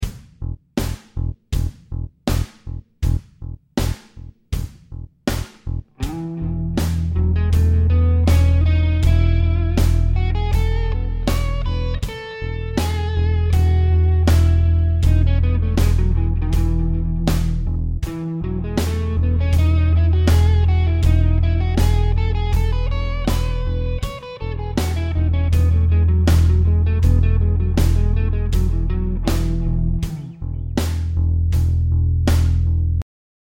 Here is a real rough guide to the different sounds of the modes.
The bass is just playing the E note in all examples.
Ionian
It sounds bright and happy.